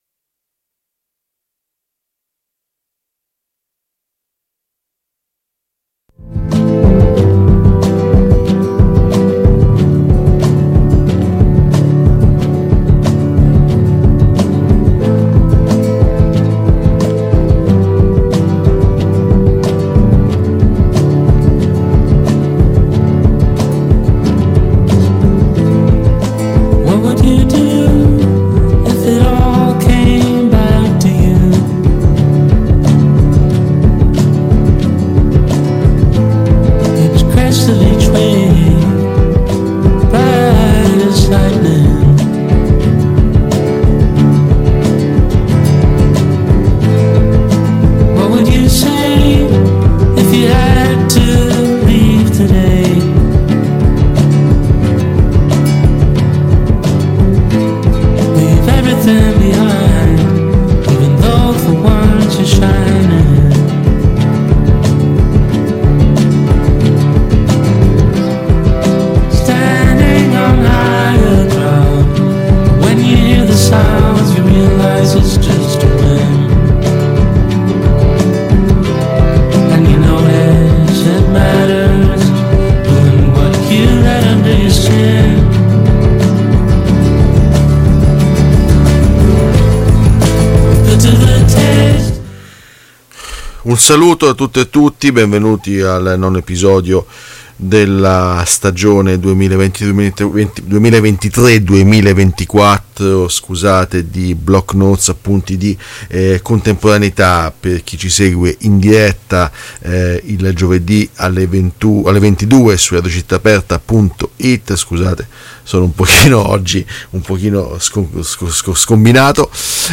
Arrivano le voci dei testimoni diretti, che hanno visto, che hanno sentito, che hanno constatato. Gaza è una città che, velocemente, muore.